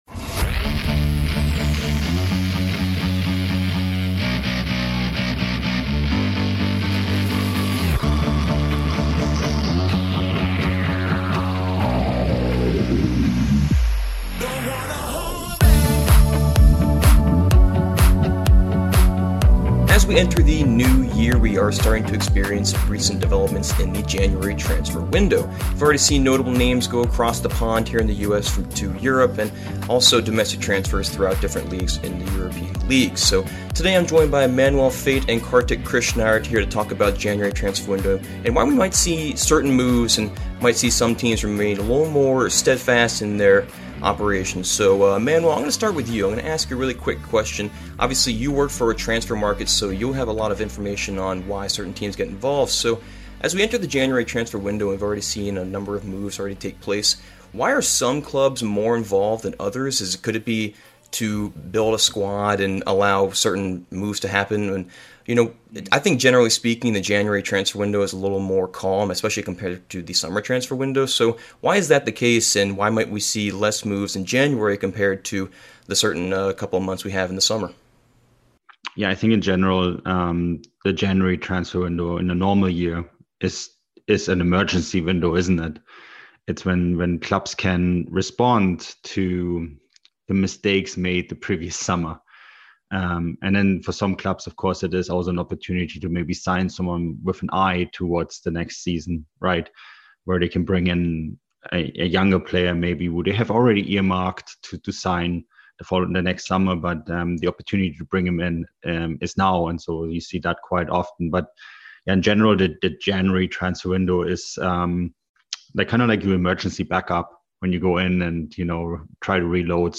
January Transfer Window Discussion With Transfermarkt